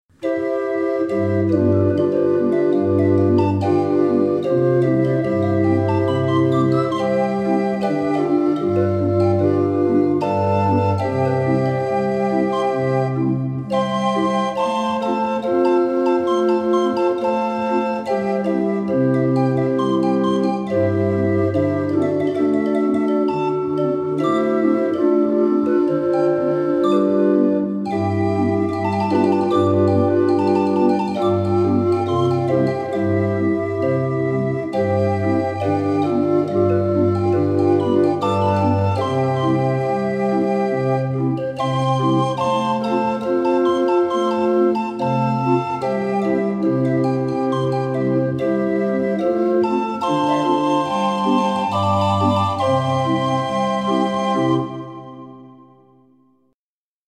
26 street organ